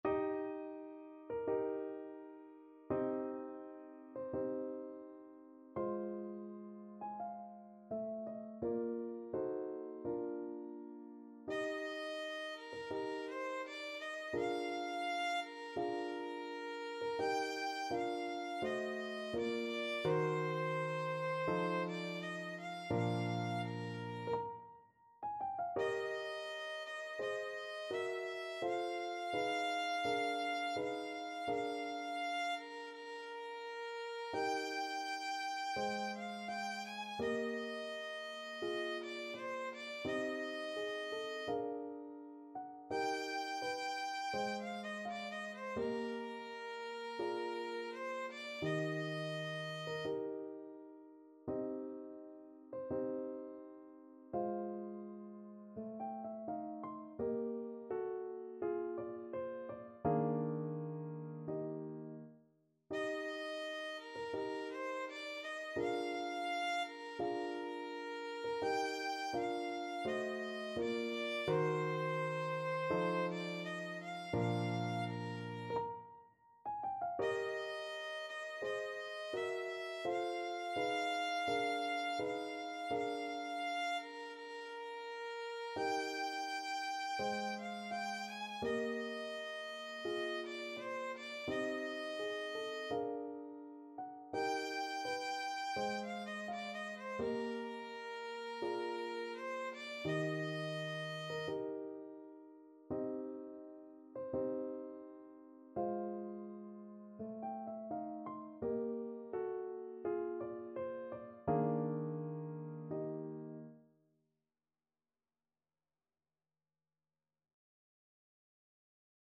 Free Sheet music for Violin
Violin
Eb major (Sounding Pitch) (View more Eb major Music for Violin )
2/4 (View more 2/4 Music)
~ = 42 Sehr langsam
Classical (View more Classical Violin Music)
an-die-sonne-d-270_VLN.mp3